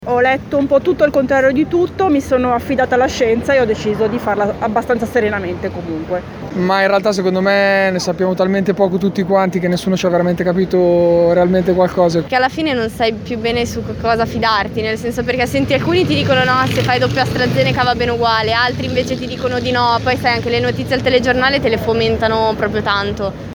voci-confusione-mix-vaccini.mp3